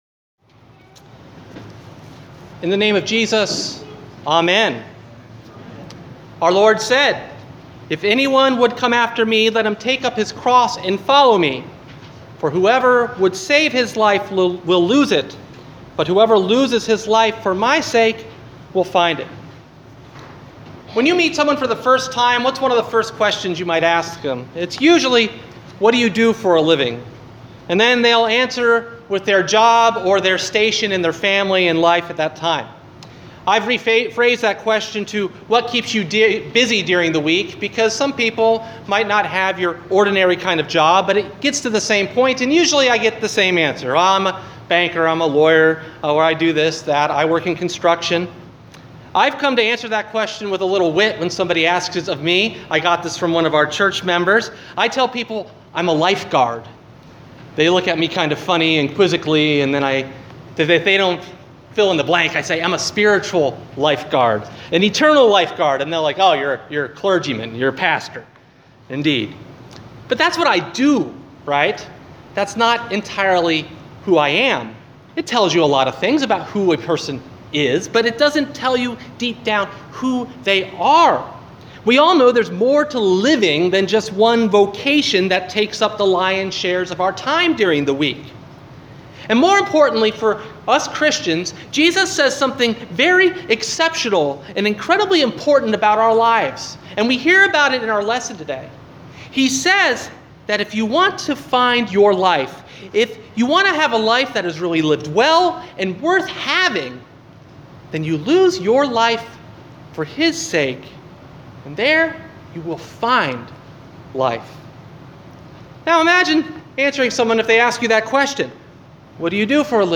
Sermon: Pentecost 13 Matthew 16:21-28